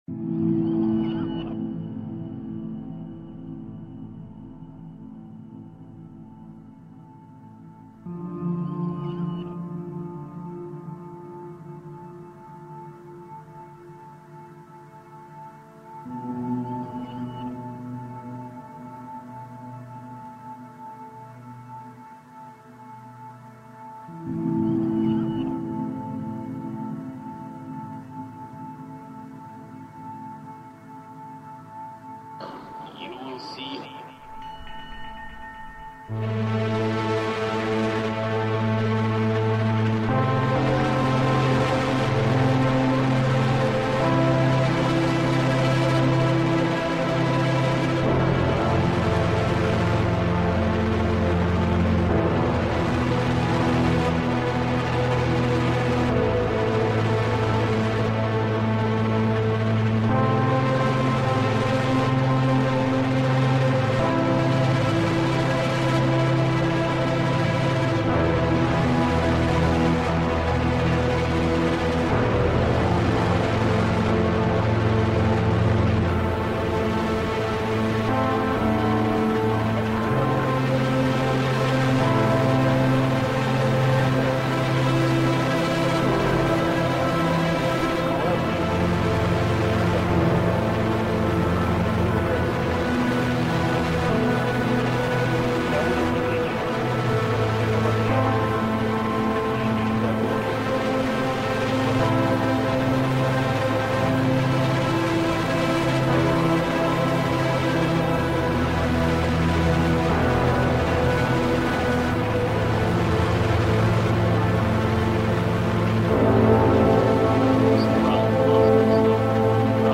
Riverboat announcements reimagined